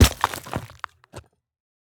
RockHitingGround_1.wav